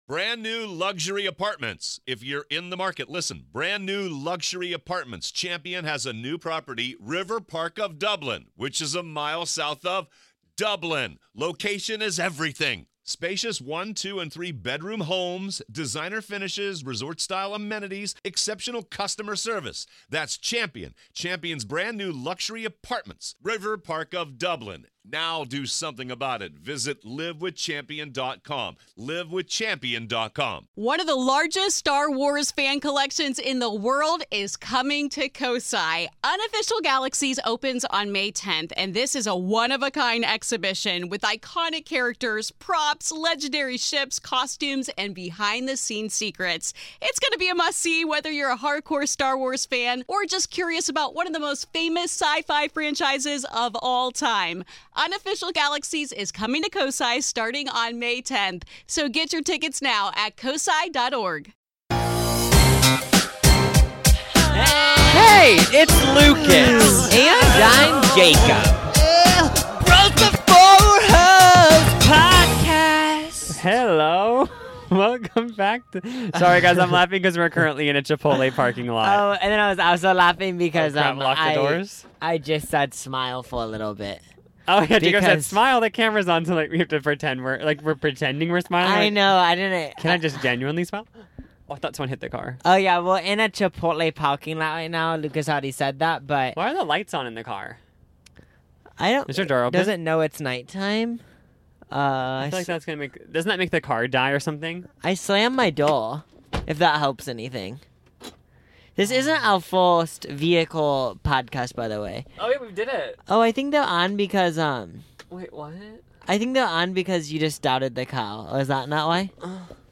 Leave us alone, we're in the car talking about some petty drama!